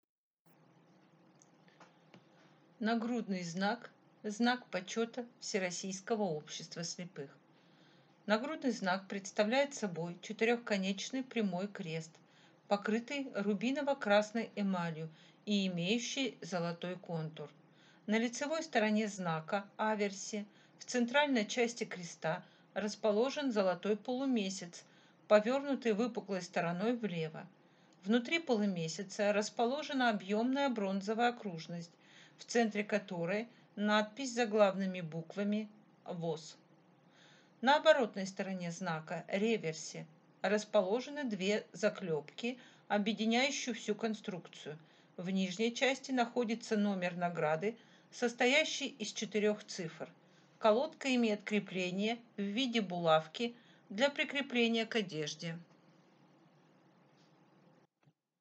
Эти голосовые метки обеспечивают звуковое сопровождение тактильных макетов наград Всероссийского общества слепых, предоставляя тифлокомментарии, которые подробно описывают каждый экспонат.